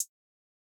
Hi-Hat (CAN_T SAY)(1).wav